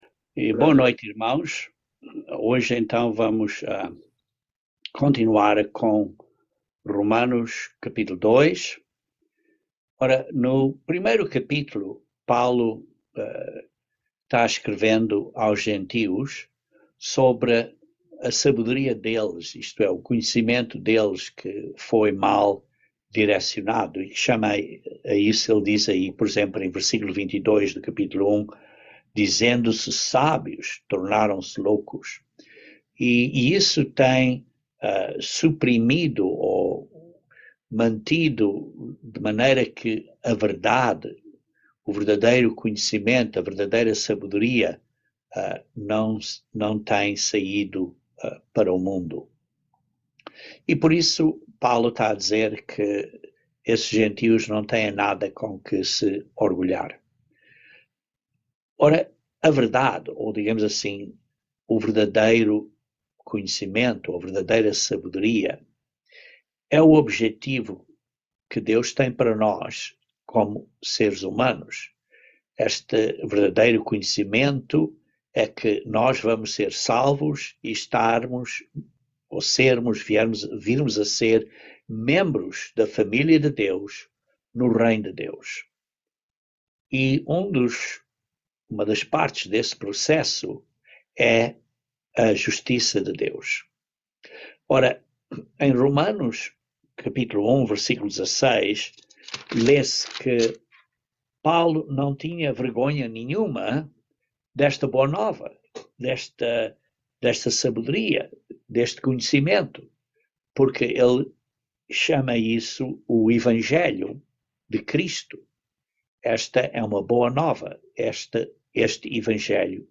Estudo Bíblico
Given in Patos de Minas, MG